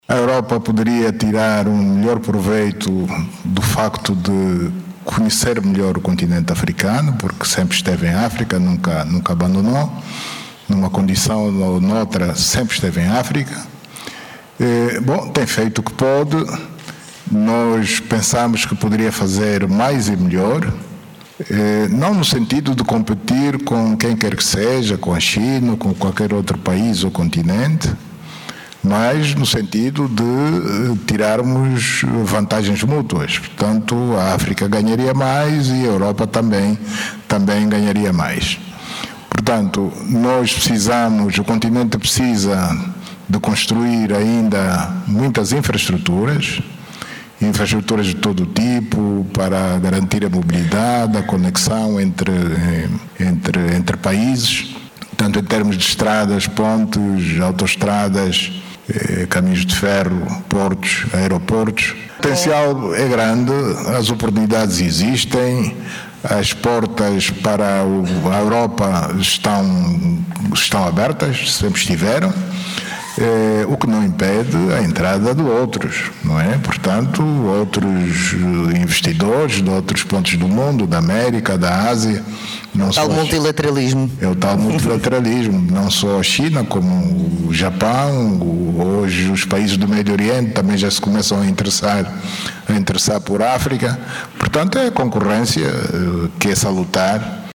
O Presidente da República de Angola, João Lourenço, participou este sábado num diálogo interativo com o Presidente Marcelo Rebelo de Sousa, que marcou o encerramento do 8.º Fórum EurAfrican, uma iniciativa do Conselho da Diáspora Portuguesa, no último dia da sua visita oficial a Portugal.
O debate, moderado por uma profissional da área da comunicação, permitiu ao Presidente João Lourenço partilhar a sua visão sobre vários temas atuais, incluindo as prioridades do desenvolvimento económico e social de Angola, a conectividade em África, o investimento europeu no continente e os desafios da inovação tecnológica, entre outros.